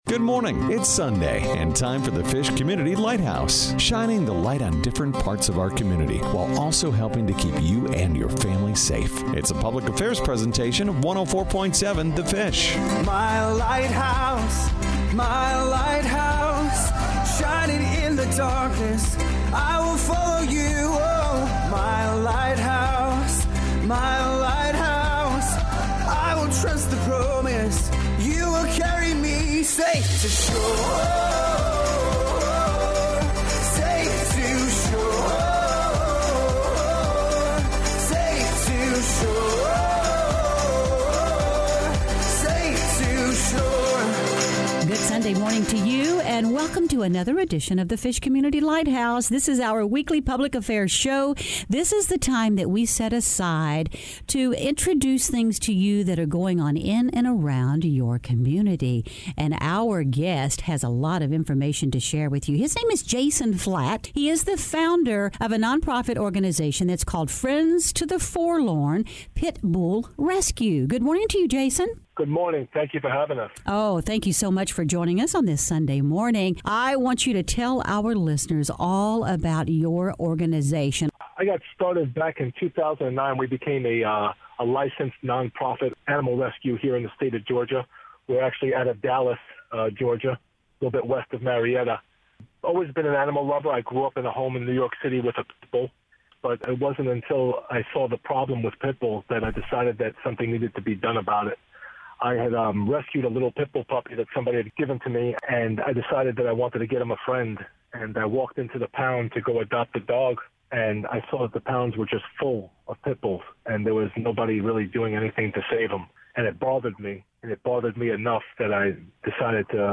104.7 The Fish Interview 5.13.18 | Friends to the Forlorn Pitbull Rescue